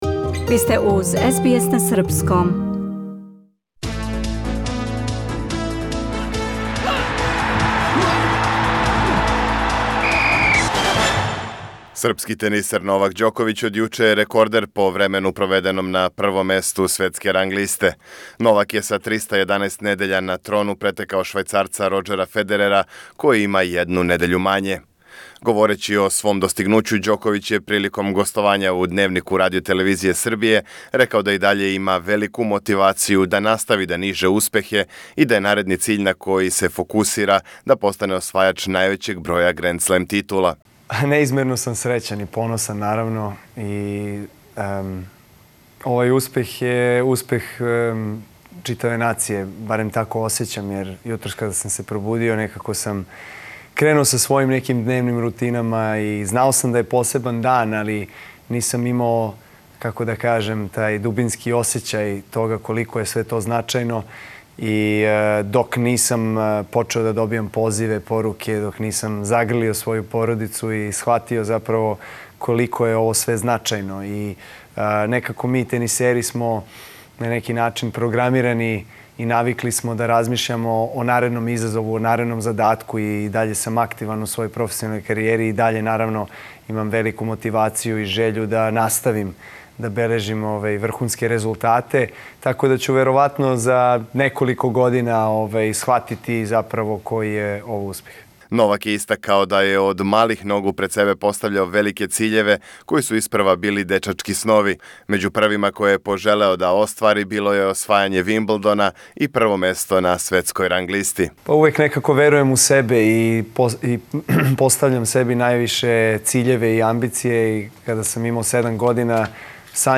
После обарања рекорда по броју недеља на врху АТП листе, најбољи светски тенисер у разговору за Дневник РТС-а изразио захвалност Србима широм света за подршку коју му пружају свих ових година. Наредни фокус постављање рекорда по броју освојених гренд слемова, али и борба за медаљу на Олимпијским играма у Токију ове године.